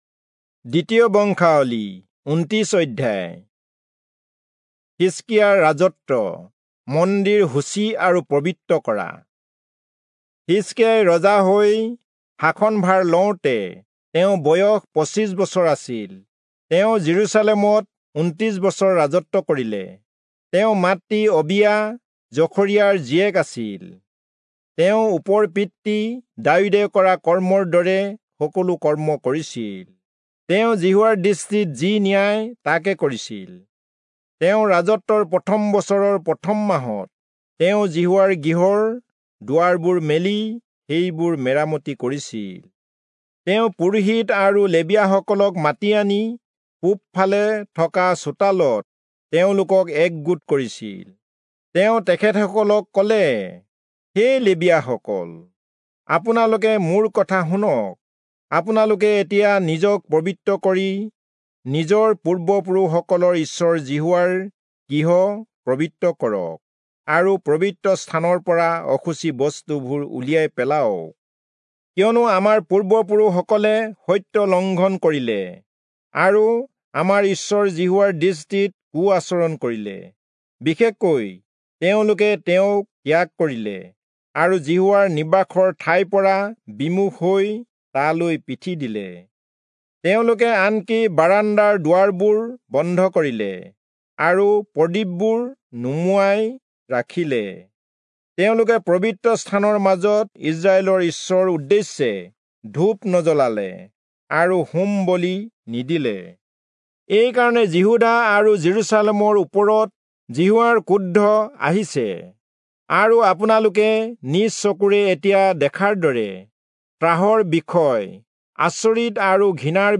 Assamese Audio Bible - 2-Chronicles 5 in Lxxrp bible version